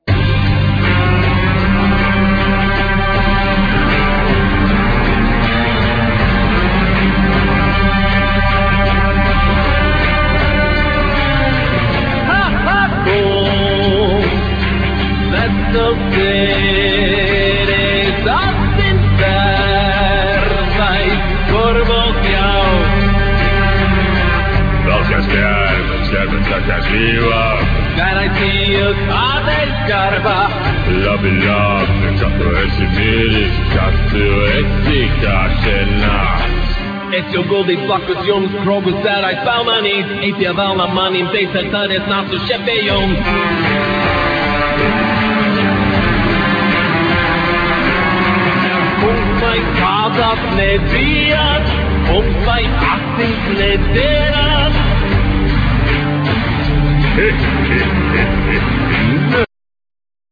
VocalViolin
Vocal,Kokle(box zither),Bagpipes,acordion,Keyboards